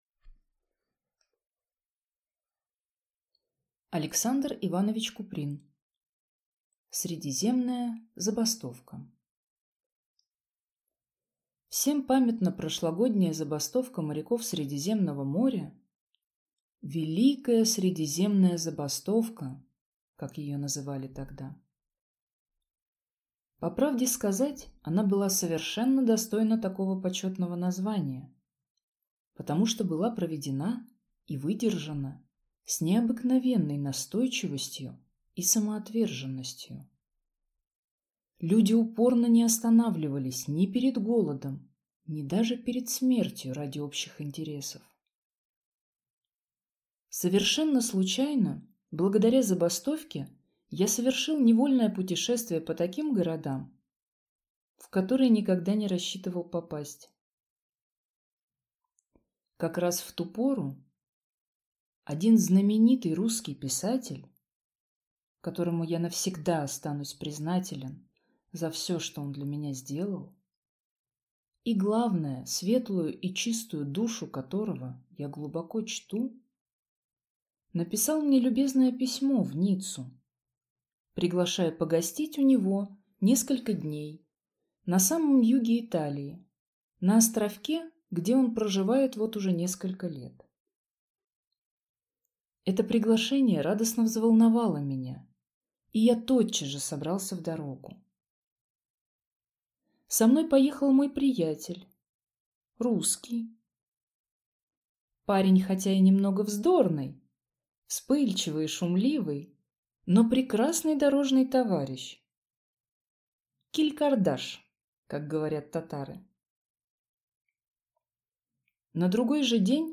Аудиокнига Средиземная забастовка | Библиотека аудиокниг